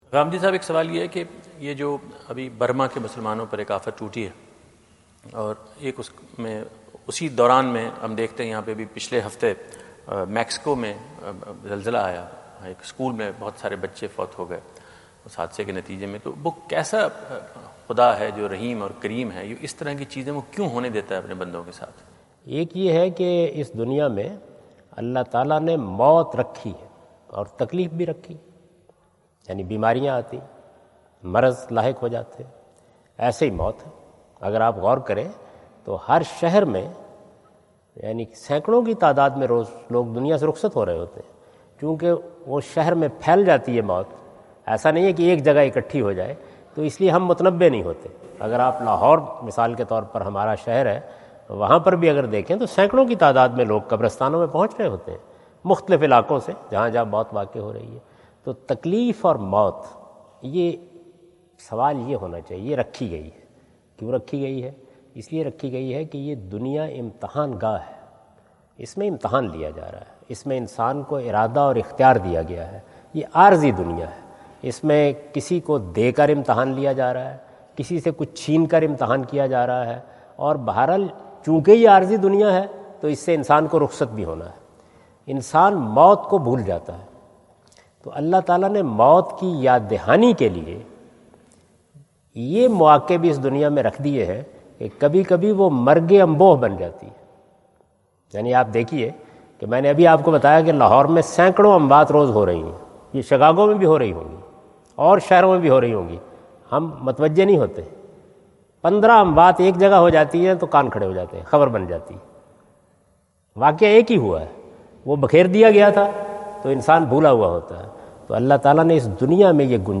Javed Ahmad Ghamidi answer the question about "Natural disasters and Allah’s benevolent personality?" During his US visit at Wentz Concert Hall, Chicago on September 23,2017.
جاوید احمد غامدی اپنے دورہ امریکہ2017 کے دوران شکاگو میں "دنیاوی آفات اور اللہ کی رحیم و کریم ذات؟" سے متعلق ایک سوال کا جواب دے رہے ہیں۔